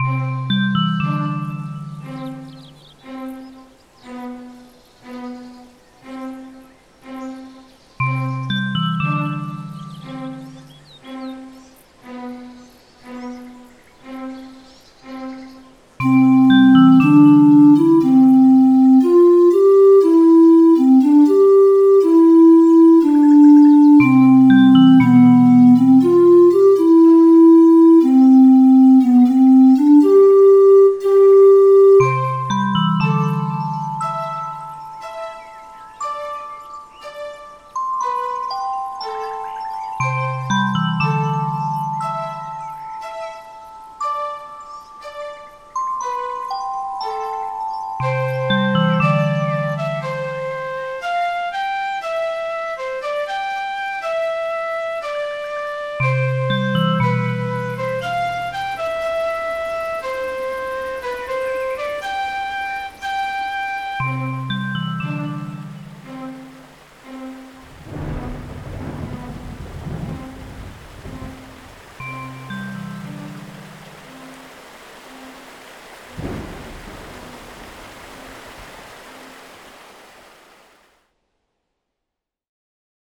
Synth Version